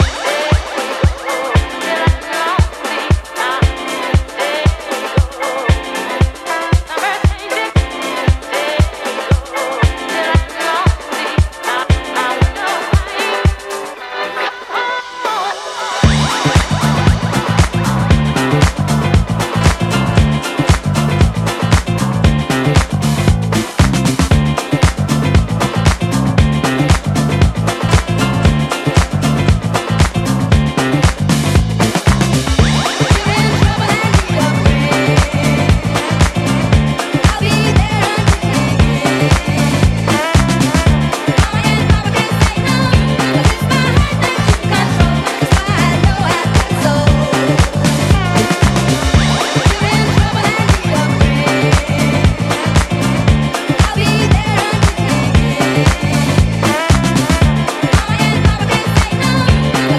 ジャンル(スタイル) ELECTRONICA / DANCE / NU JAZZ / FUNK / SOUL